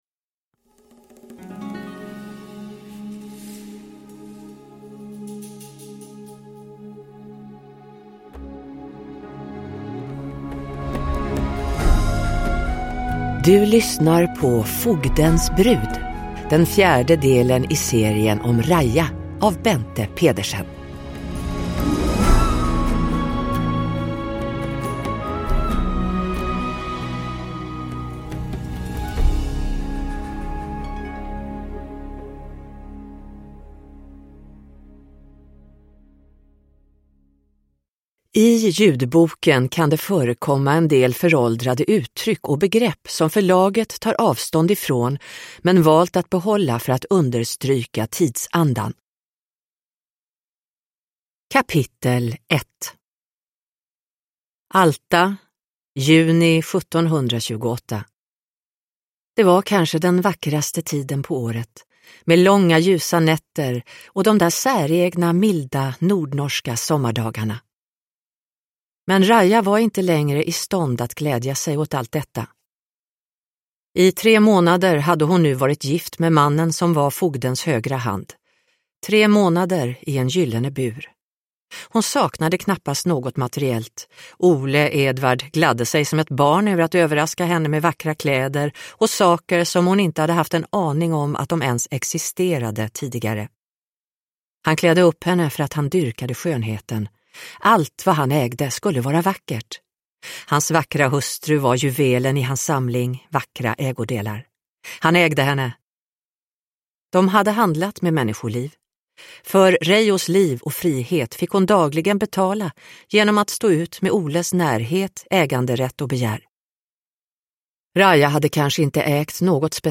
Fogdens brud – Ljudbok – Laddas ner